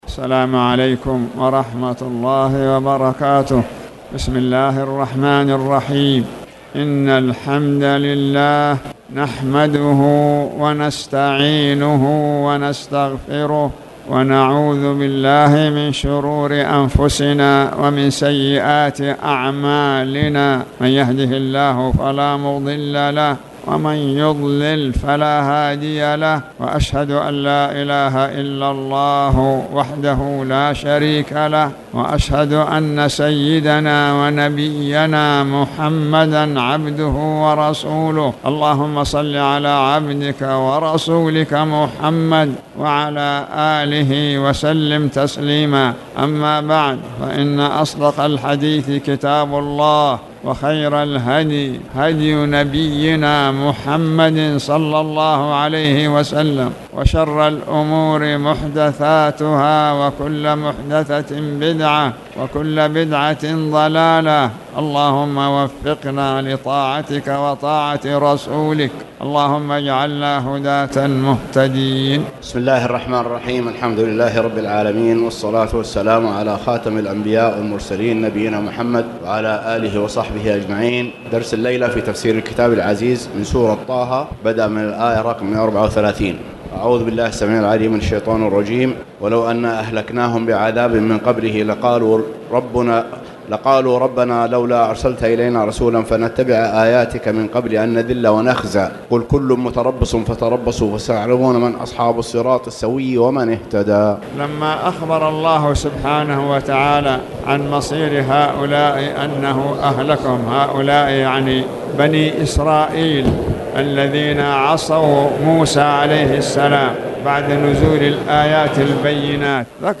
تاريخ النشر ٢٨ شعبان ١٤٣٨ هـ المكان: المسجد الحرام الشيخ